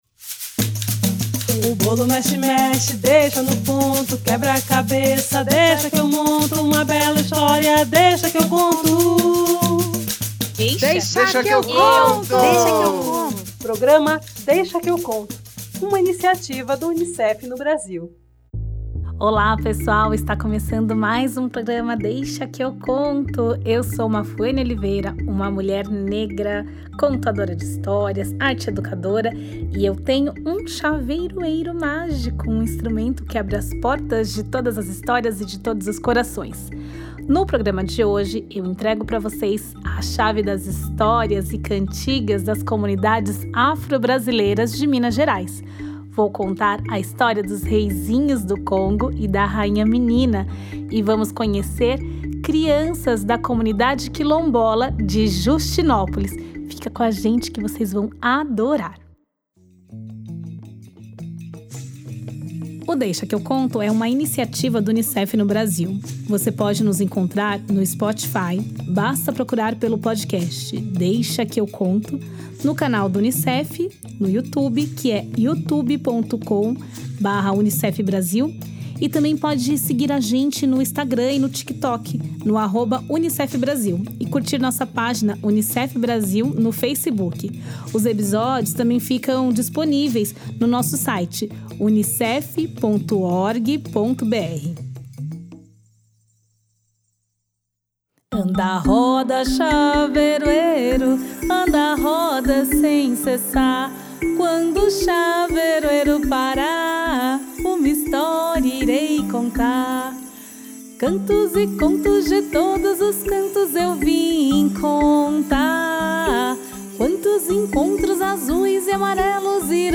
Narra a história dos Reizinhos do Congo e da Rainha Menina. Vamos brincar de “Navio Apitou” e conhecer as crianças da comunidade quilombola de Justinópolis numa entrevista divertida.